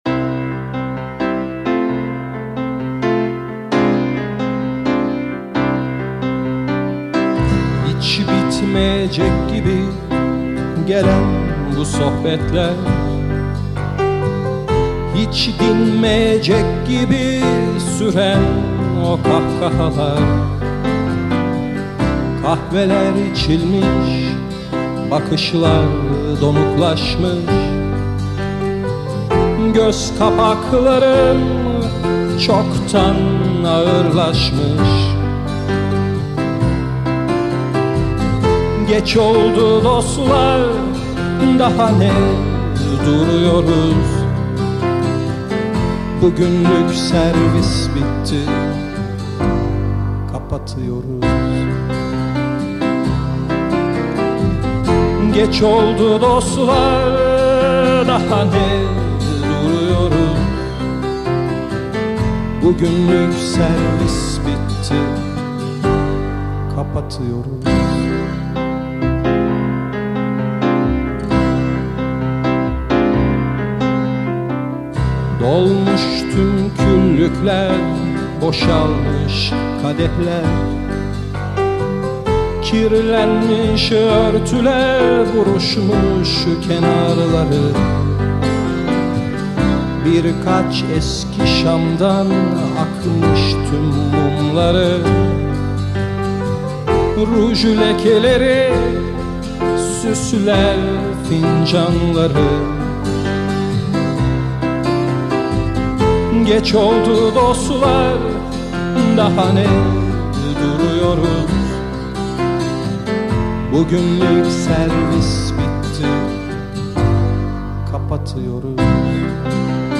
Bir adet de ev demo kaydı içerir.
Vokal, akustik gitar, armonika
Bas gitar
Piyano
Akustik gitar